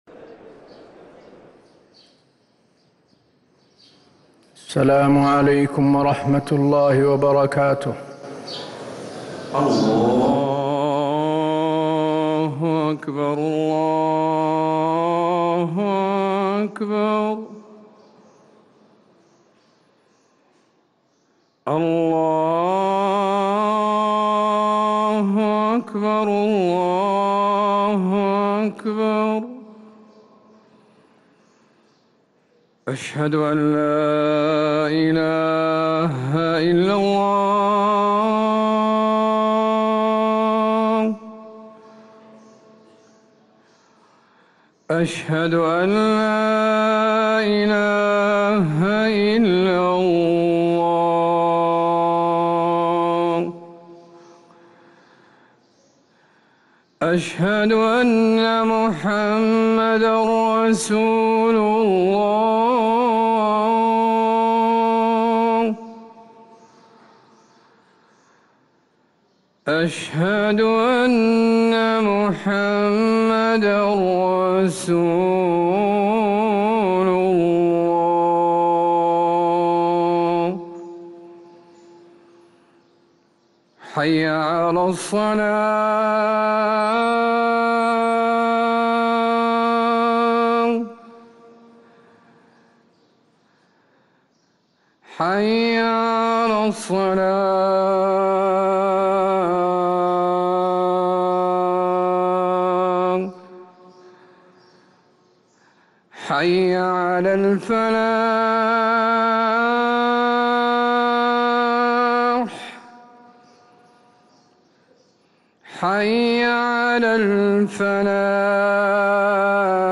أذان الجمعة الثاني